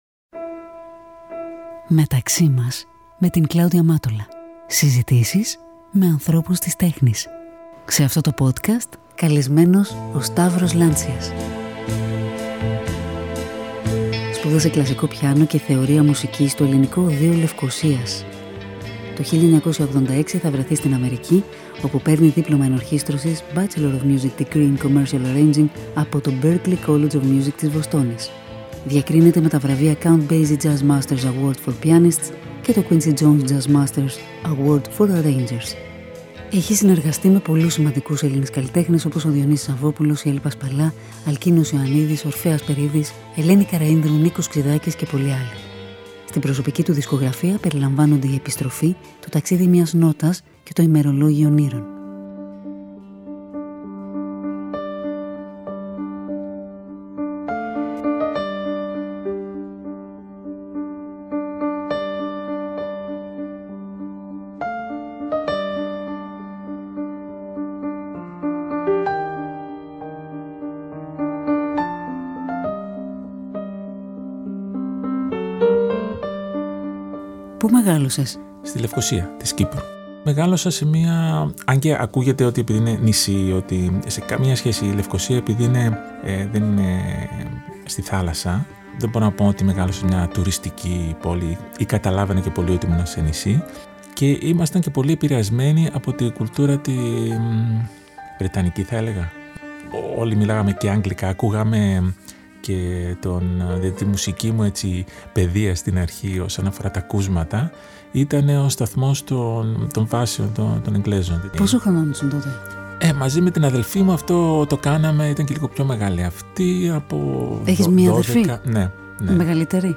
Μια οργανική συζήτηση με ανθρώπους της τέχνης.